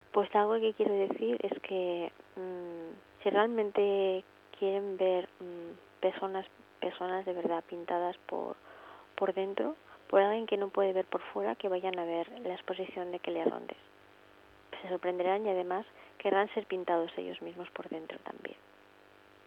suena cercana, increíblemente dulce, aniñada, con colores de tonos suaves, casi infantiles.